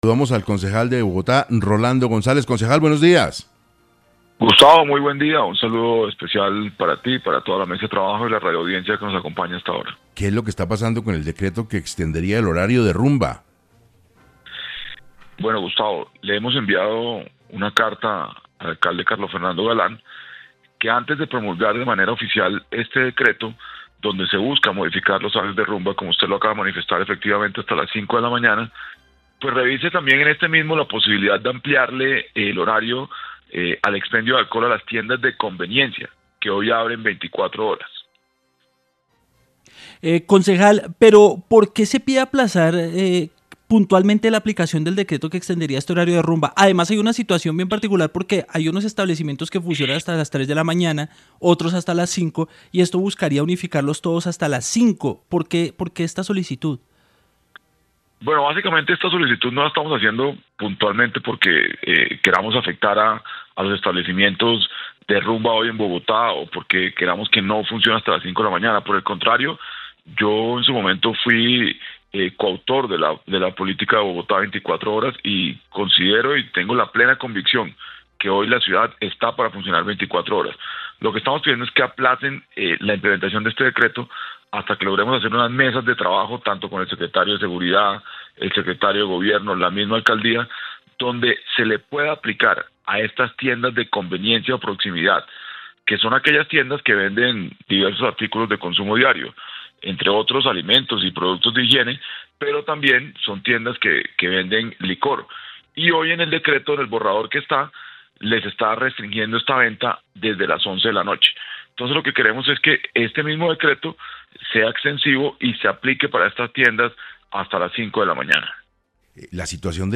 El concejal Rolando González explicó en 6AM los motivos que impulsaron la solitud al alcalde Carlos Fernando Galán.
En entrevista para 6AM, el concejal Rolando González, quien ya envió una carta al alcalde Carlos Fernando Galán pidiendo que revise algunas condiciones de la medida.